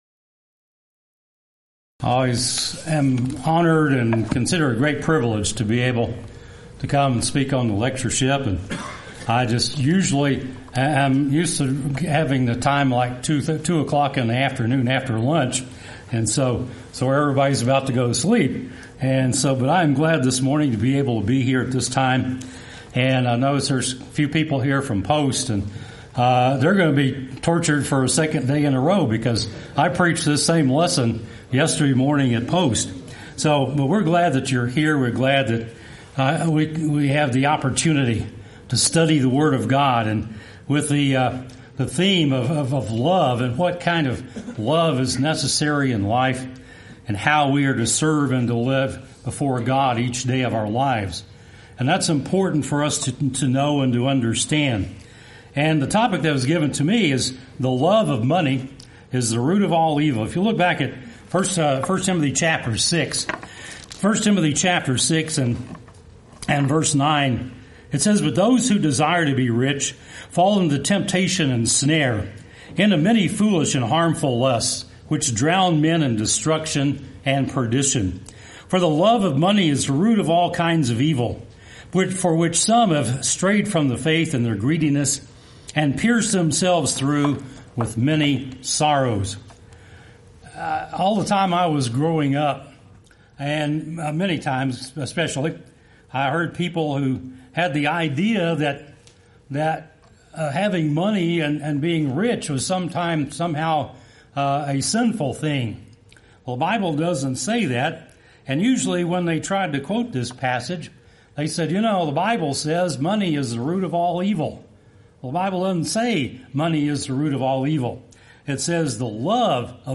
Event: 26th Annual Lubbock Lectures